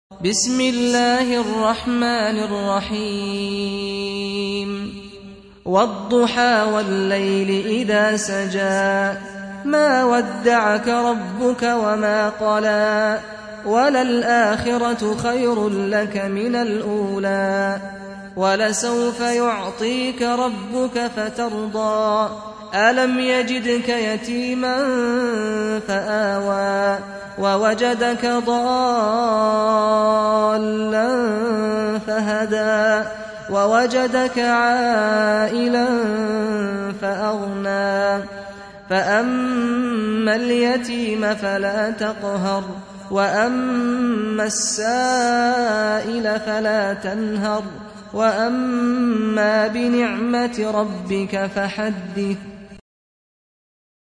93. Surah Ad-Duha سورة الضحى Audio Quran Tarteel Recitation
Surah Repeating تكرار السورة Download Surah حمّل السورة Reciting Murattalah Audio for 93. Surah Ad-Duha سورة الضحى N.B *Surah Includes Al-Basmalah Reciters Sequents تتابع التلاوات Reciters Repeats تكرار التلاوات